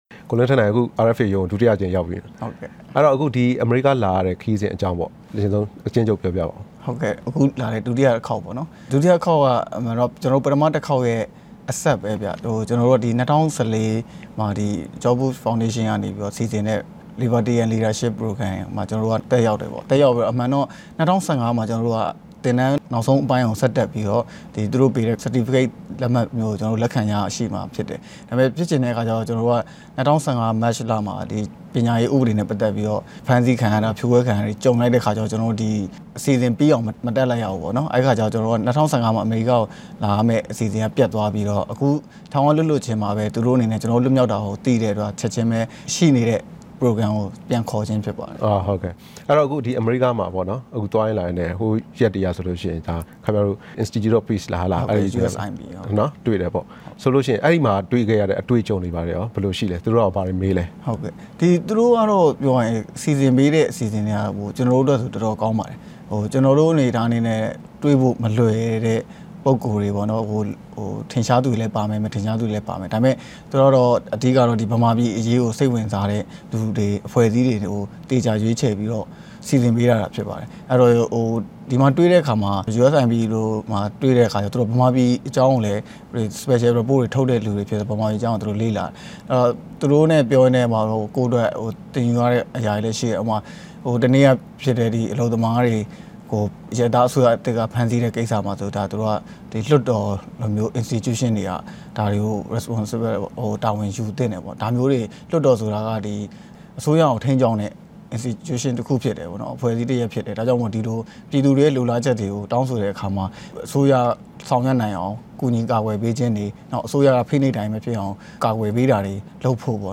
တွေ့ဆုံခြင်း